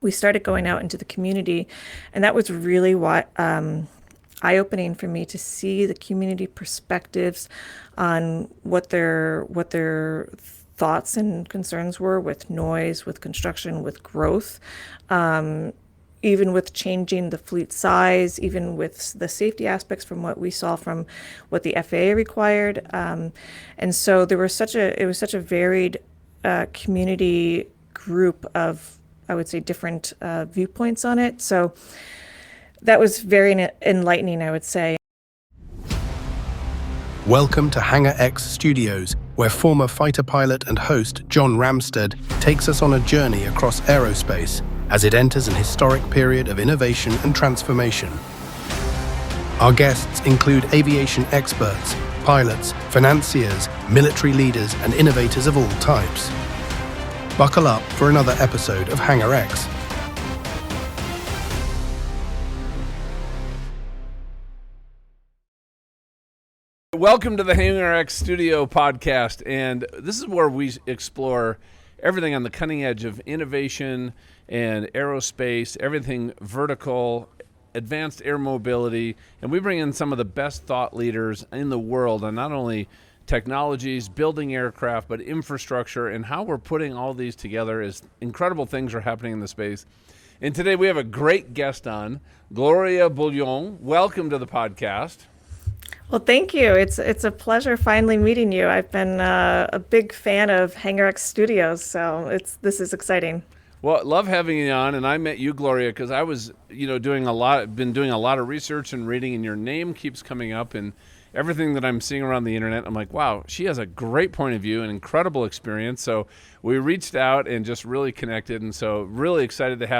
She delves into the challenges and opportunities small airports face, the practicalities of community engagement, the evolving landscape of regional and urban air mobility, and the significance of initiatives like the upcoming Pulitzer Electric Air Race. This conversation is a masterclass in how the aviation industry can innovate responsibly, sustainably, and inclusively.